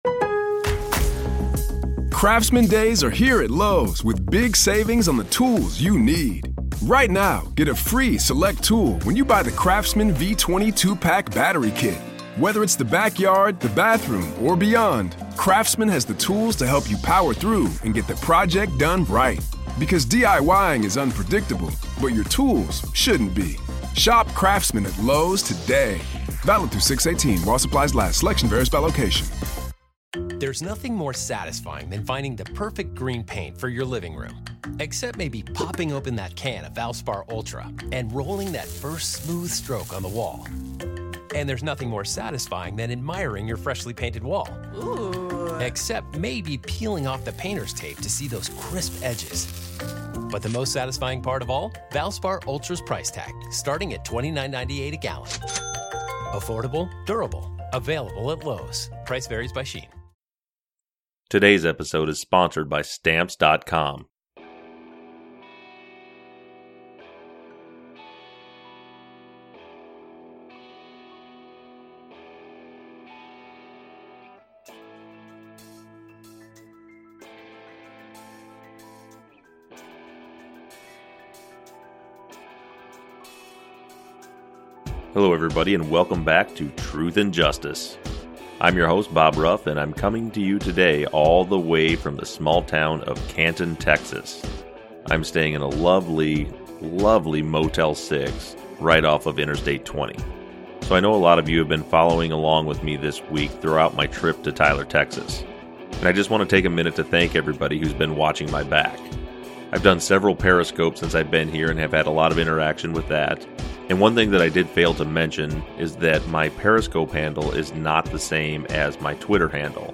interviews the FBI agent